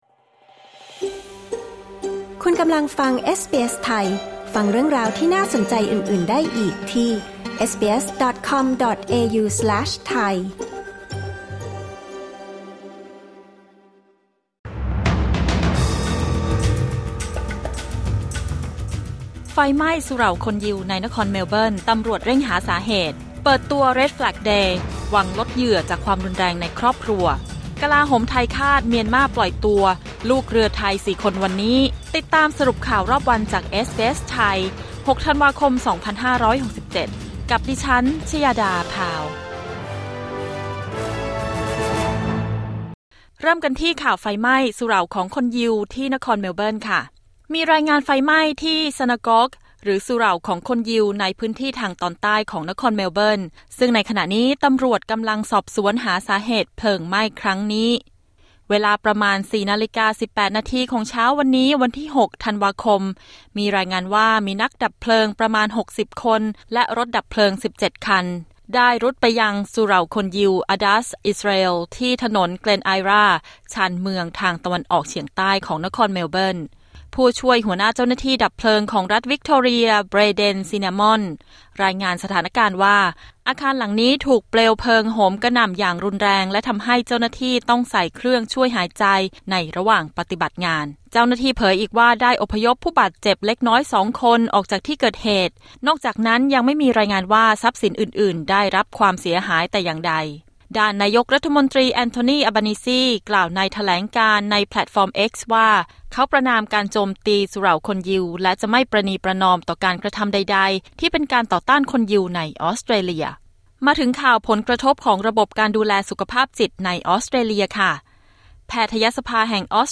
สรุปข่าวรอบวัน 6 ธันวาคม 2567